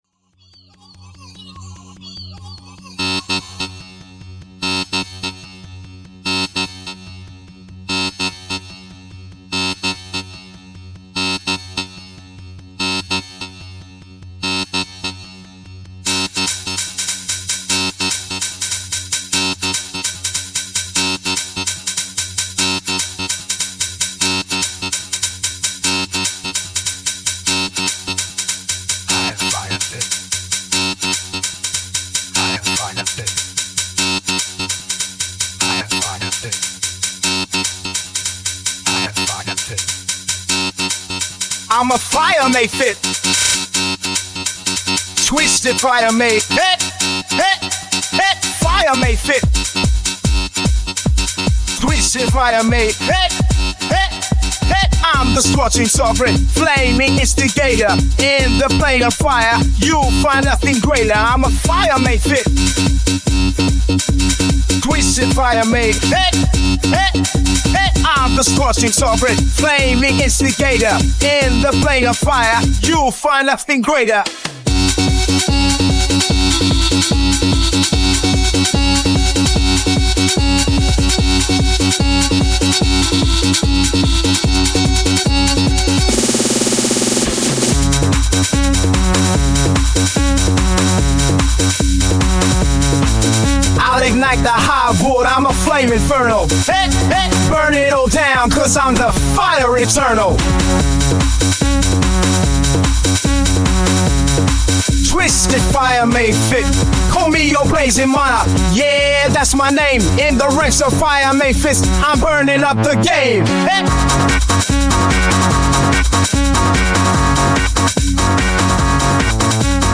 Music Poetry